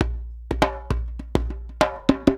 Index of /90_sSampleCDs/USB Soundscan vol.36 - Percussion Loops [AKAI] 1CD/Partition A/19-100JEMBE
100 JEMBE4.wav